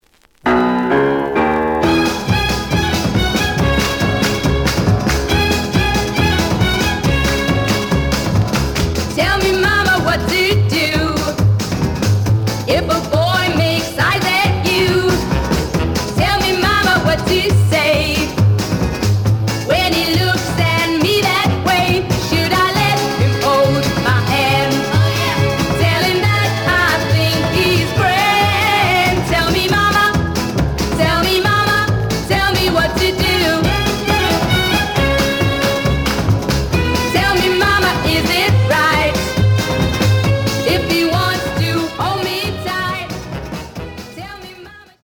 The audio sample is recorded from the actual item.
●Genre: Rhythm And Blues / Rock 'n' Roll
Slight damage on both side labels. Plays good.)